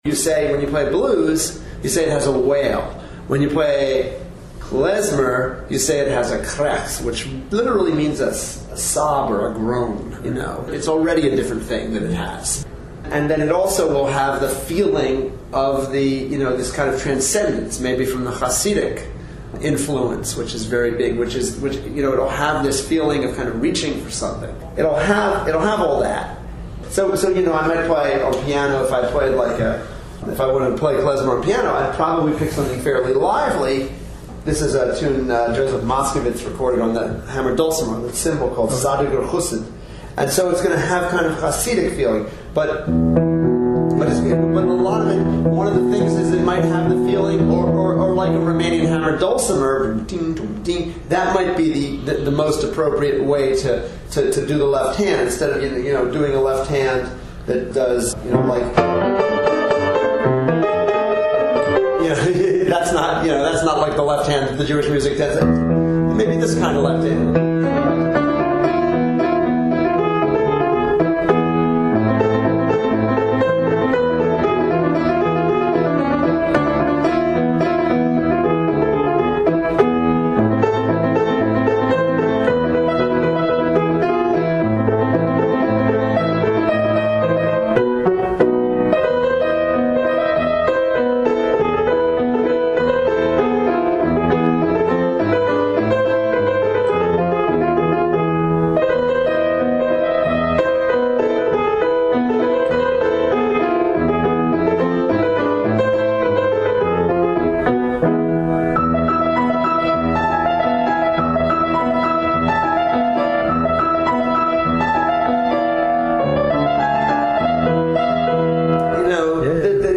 Brief audio excerpts from Netsky’s interview for Jewish Sages of Today